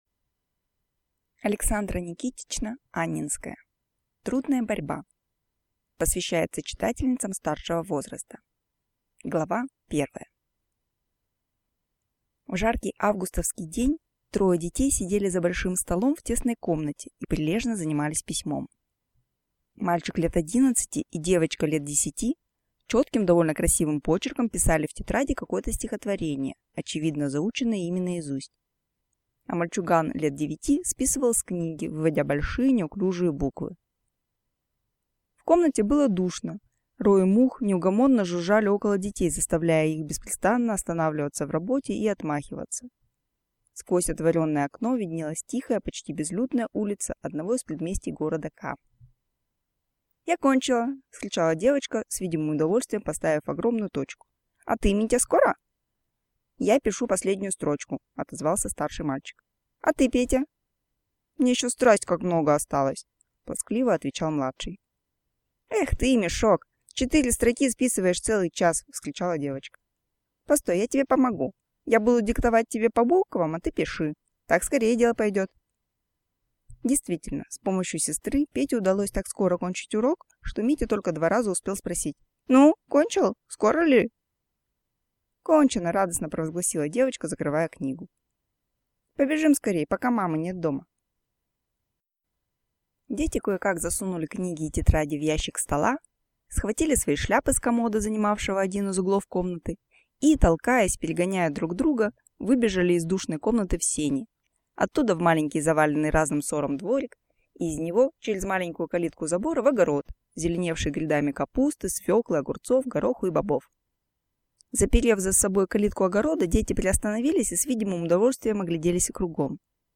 Aудиокнига Трудная борьба